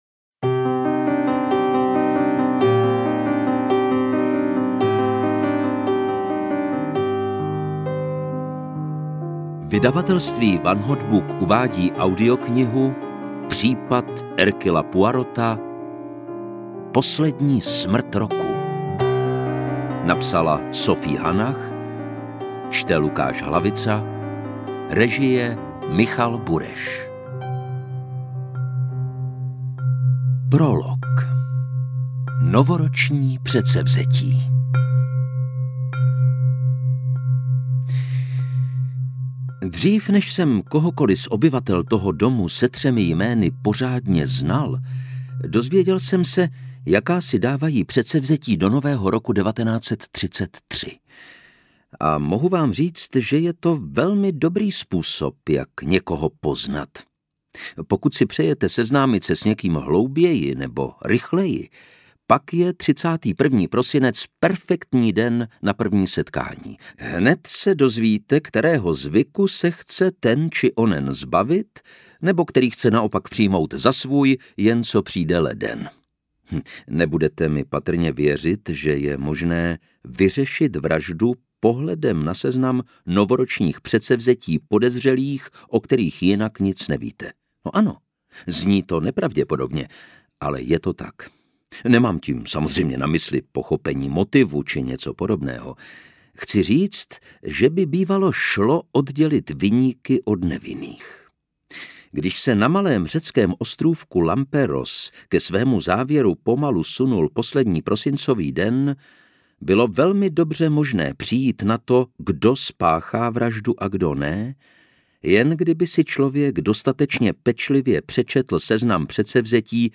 Interpret:  Lukáš Hlavica